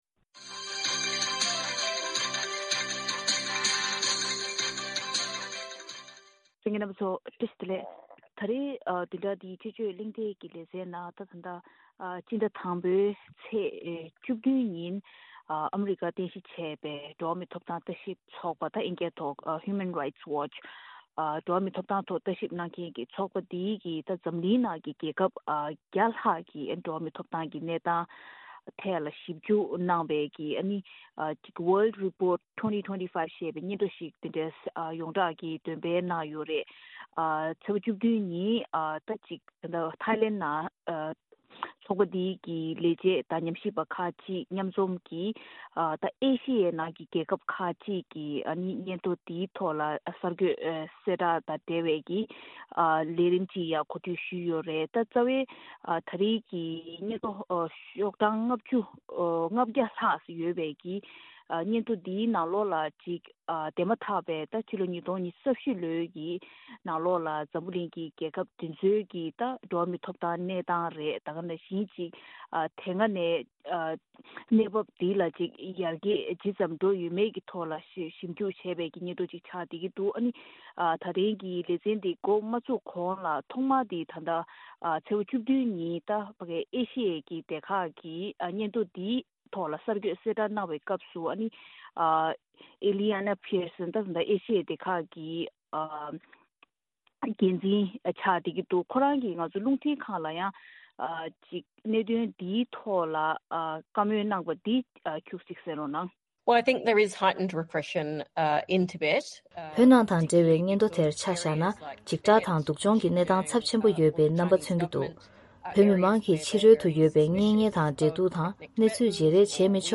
དཔྱད་གླེང་གནང་བའི་ལས་རིམ།